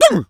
Animal_Impersonations
turkey_ostrich_hurt_yelp_01.wav